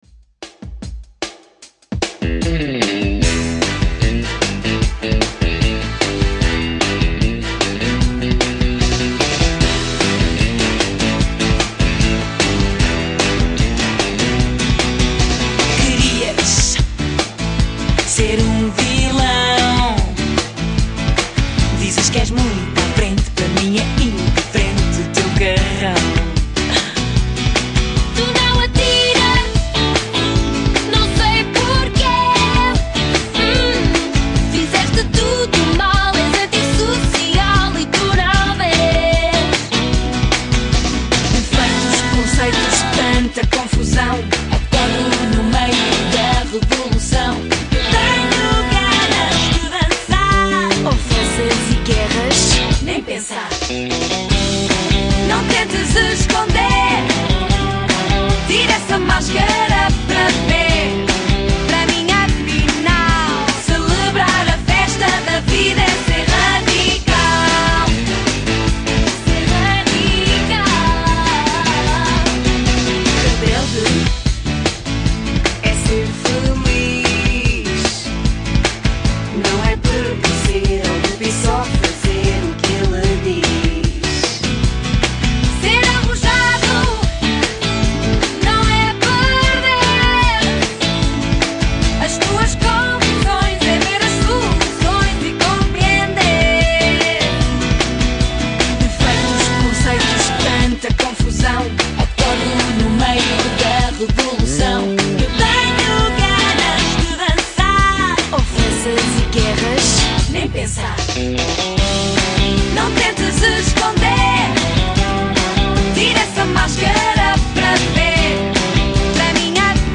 In my radio show, I present artists, stories, and sounds that reveal the soul of Portugal.